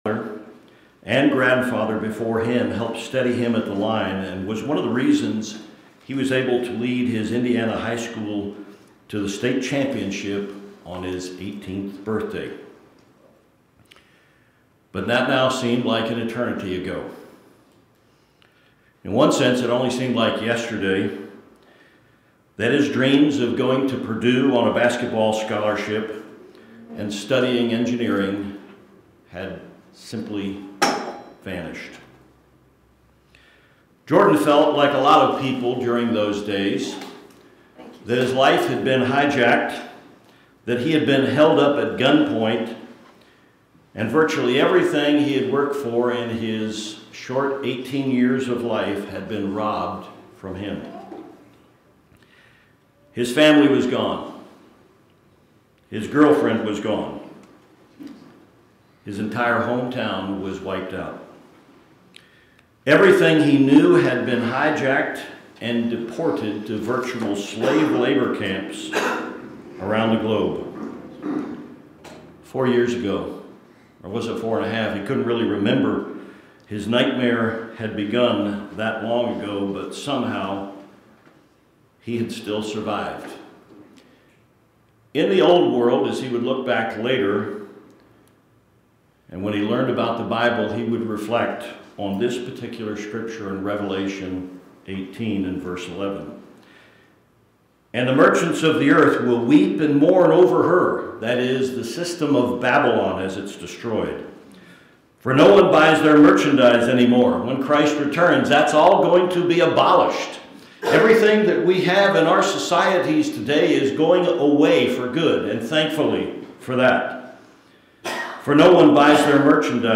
This sermon focuses on the life of a young man who survives the Great Tribulation and Day of the Lord and begins his life as the first generation to live a full life after Christ's return.